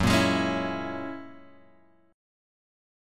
F#9 chord {2 1 2 1 x 0} chord